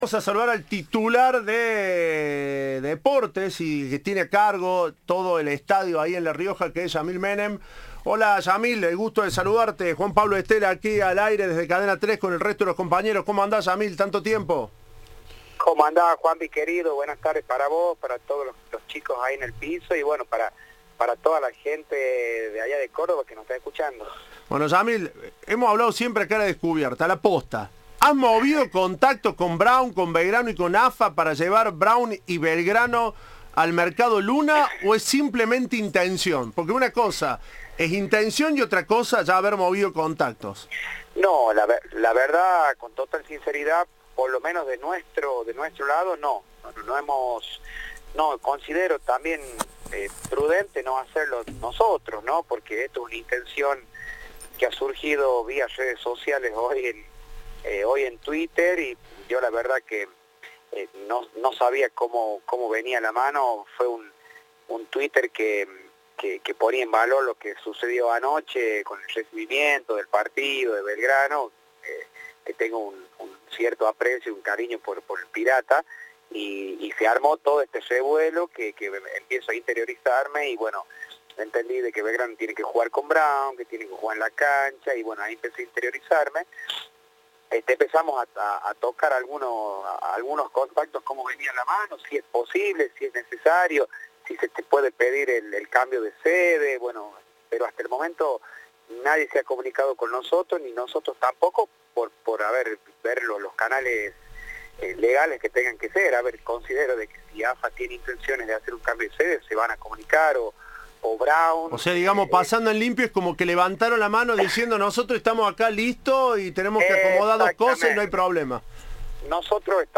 Entrevista de "Tiempo de Juego"